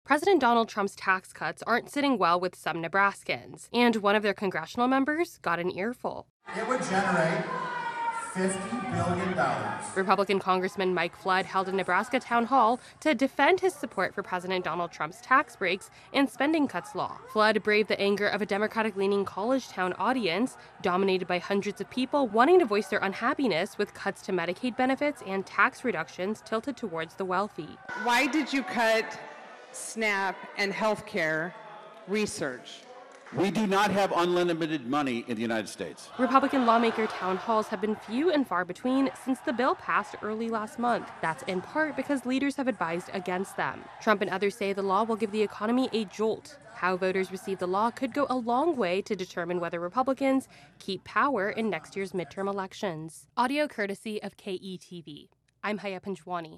Nebraska Republican is shouted down by a hostile crowd at a town hall on Trump's tax cuts
AP correspondent